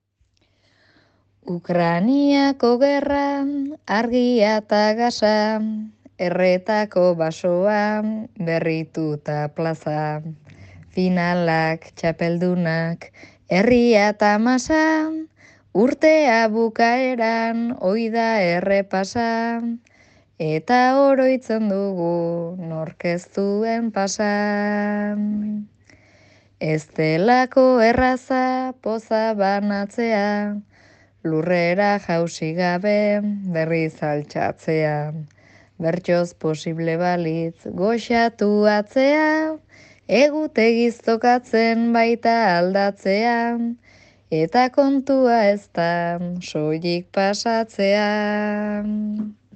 'Pasa' bertso sortarekin.